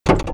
IDG-A32X/Sounds/Cockpit/relay-apu.wav at 9cc0a1f785c69a21e3c66ba1149f7e36b5fb7c4d
relay-apu.wav